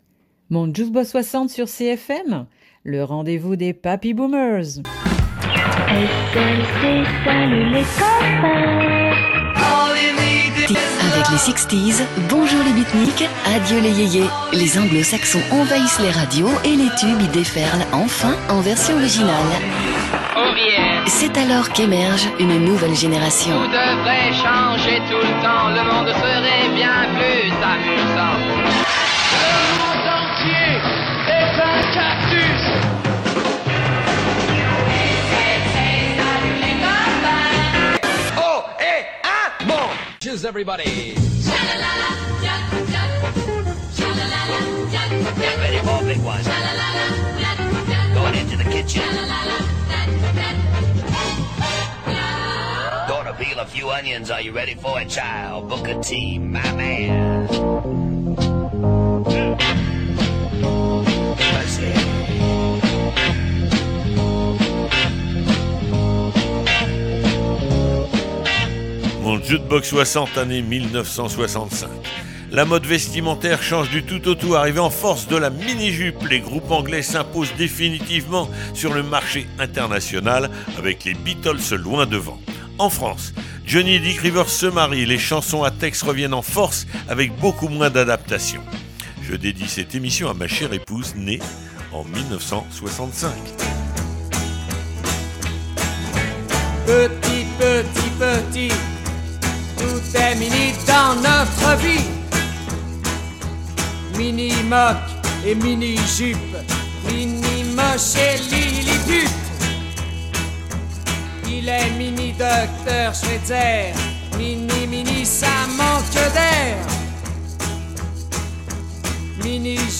Visite des tubes de l’année 1965 , interview de Françoise Hardy, Christophe, Dani et Claude Francois, explications sur le phénomène Mods & Rockers .